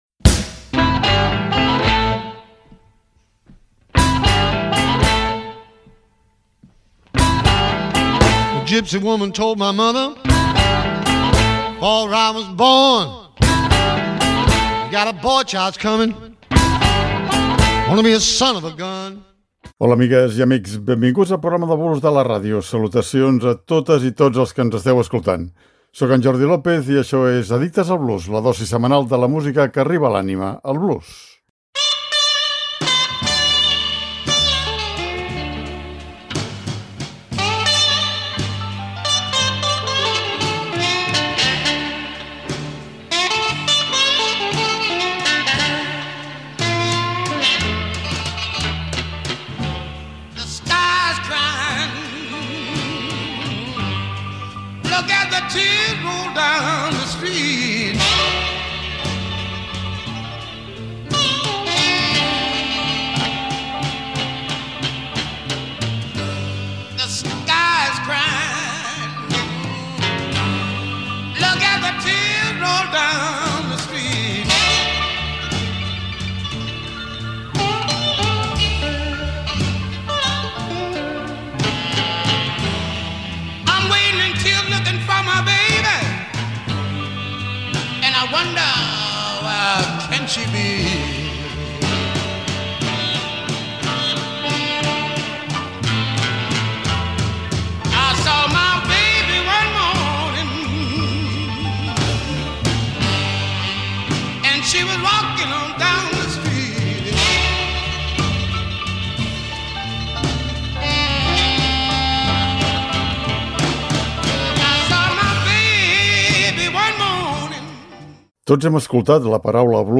Per aquesta raó, seguim recollint alguns dels èxits del blues que van marcar una fita en la seva època i que encara continuen sent considerades avui dia obres mestres del gènere i de la música en general.